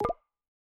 Bamboo Pop v2 Notification4.wav